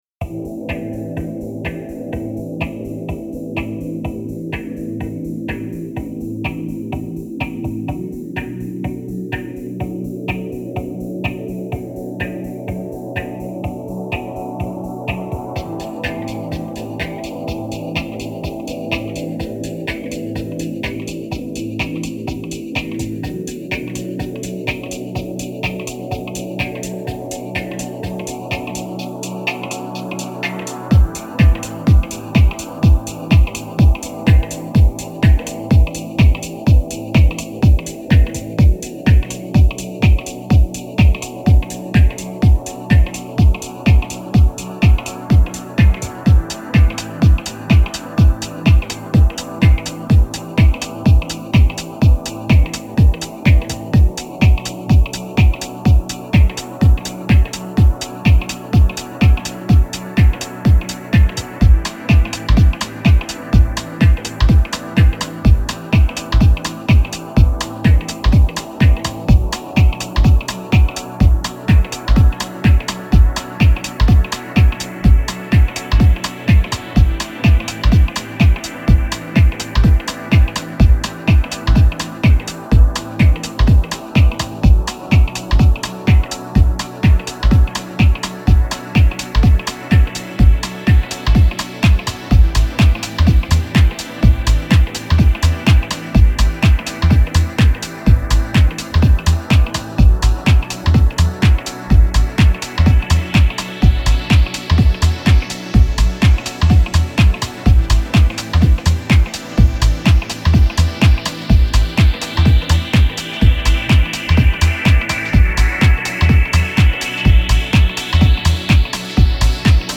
A 30 minute concert